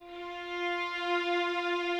strings_053.wav